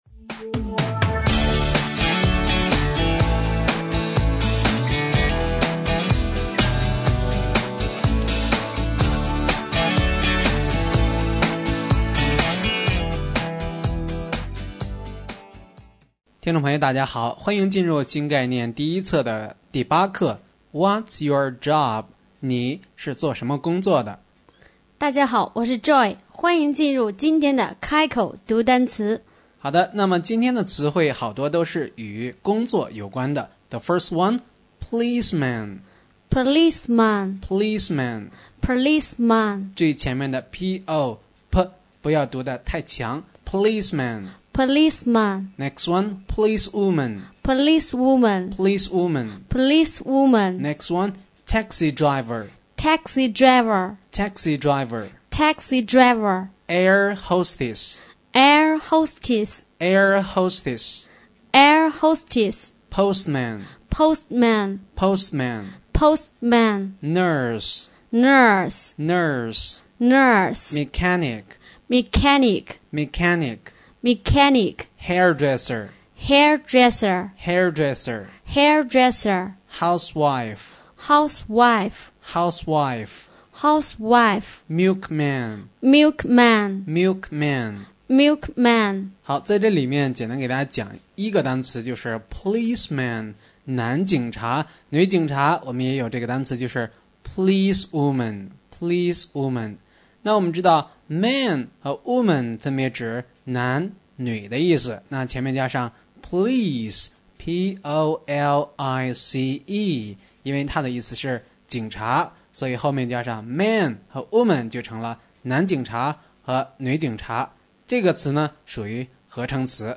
开口读单词